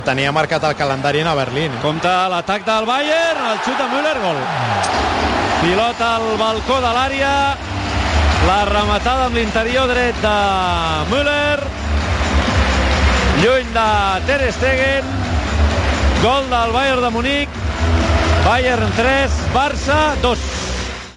Transmissió del partit de tornada de la fase eliminatòria de la Copa d'Europa de futbol masculí entre el Bayern München i el Futbol Club Barcelona.
Narració del tercer gol del Bayern München, marcat per Müller.
Esportiu